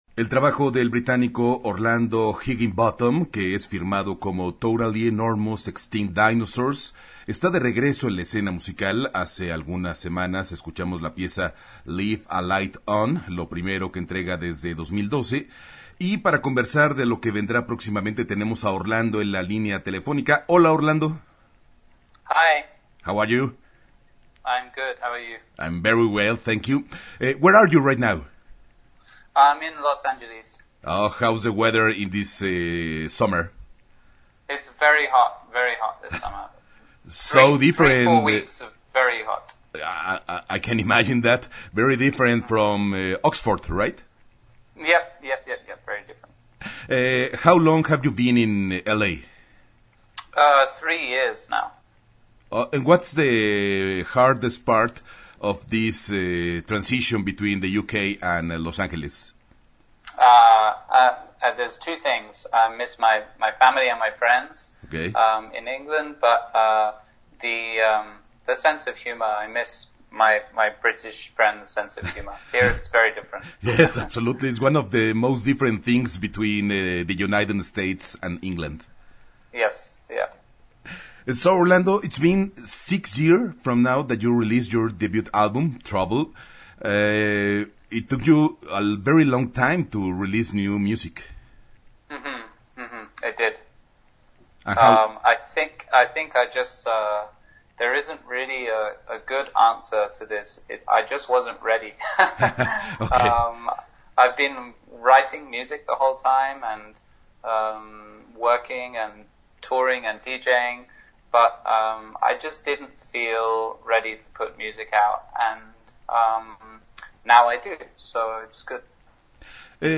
Entrevista-Totally-Enormous-Extinct-Dinosaurs-web.mp3